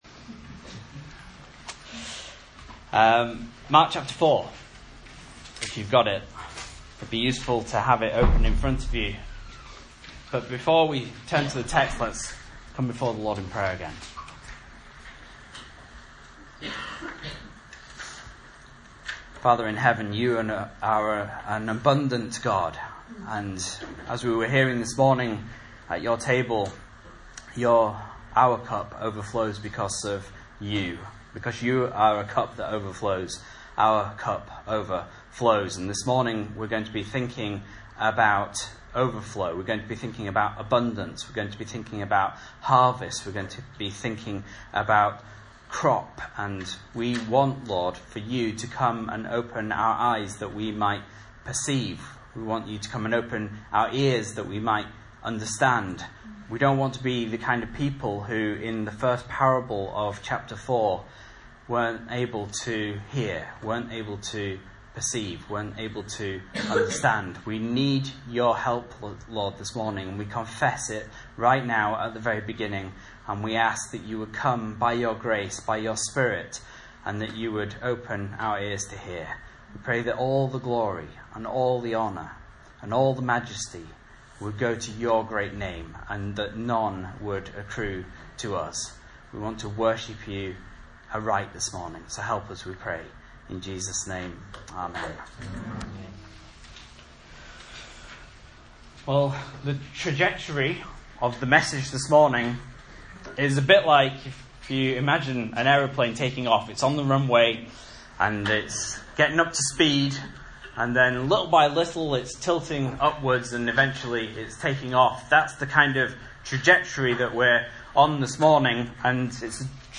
Message Scripture: Mark 4:21-34 | Listen